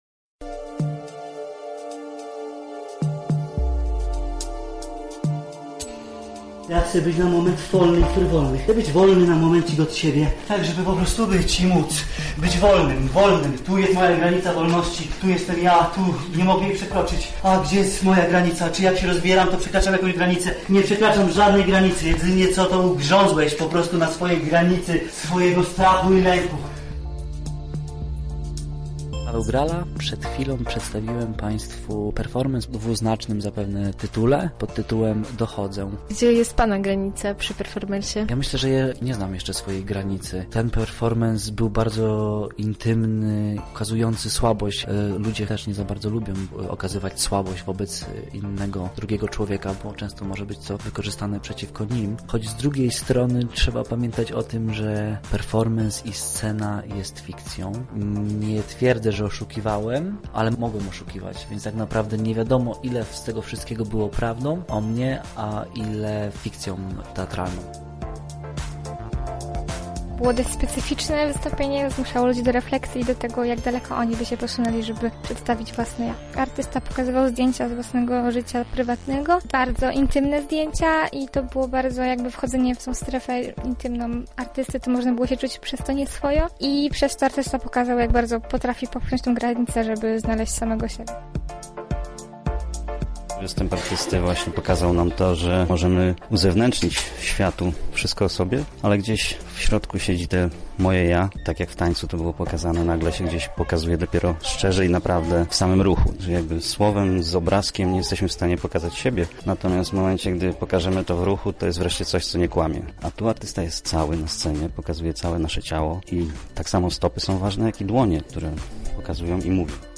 Na festiwalu zaprezentowały się grupy artystyczne z całej Polski.